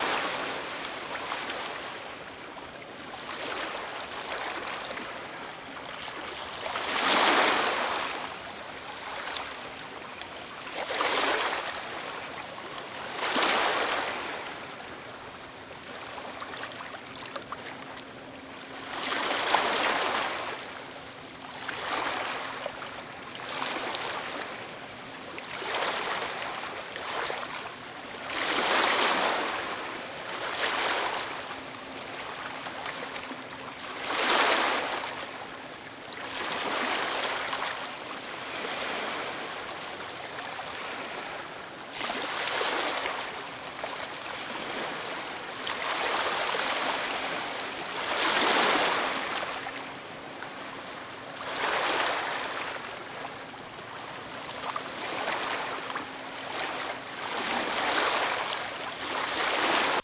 ocean.ogg